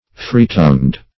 Free-tongued \Free"-tongued`\, a. Speaking without reserve.